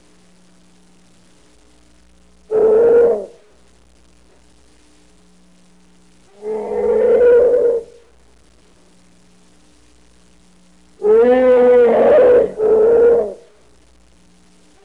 Growling Bear Sound Effect
Download a high-quality growling bear sound effect.
growling-bear.mp3